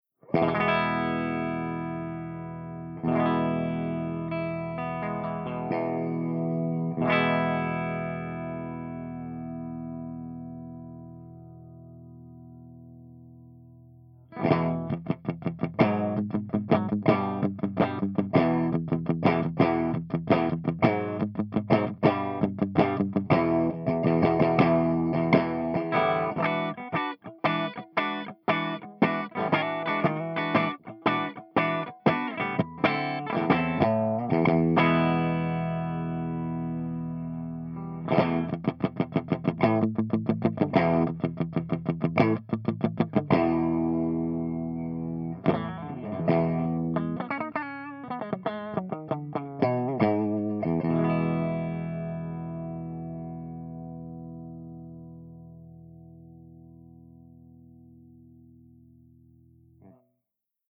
145_MESA SINGLE RECTIFIER_CH1CLEAN_V30_HB